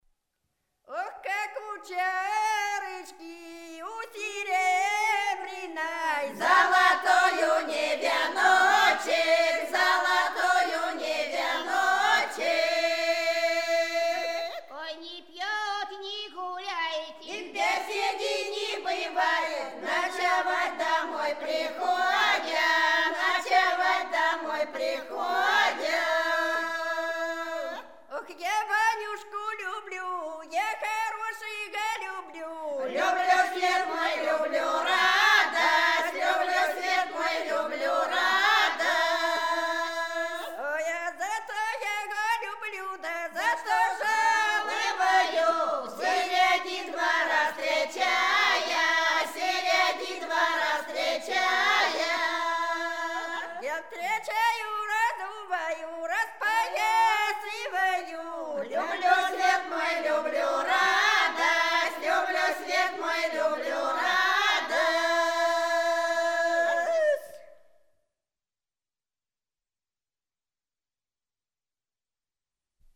Рязань Секирино «Ох, как у чарочки», свадебная.